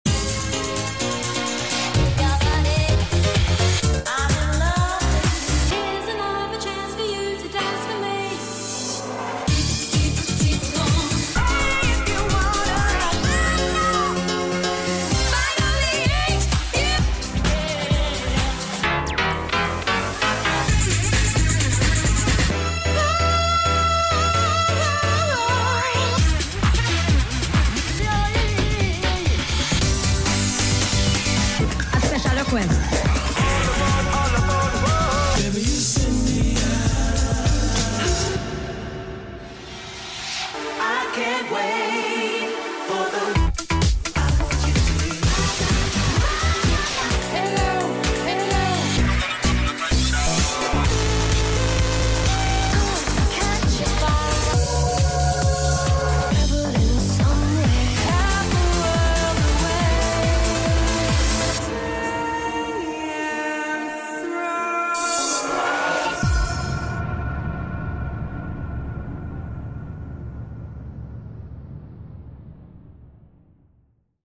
Some incredible motors at Portrush sound effects free download